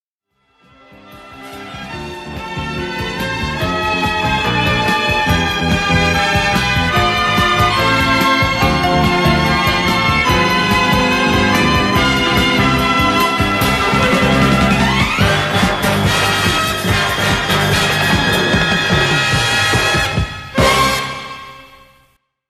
Future Funk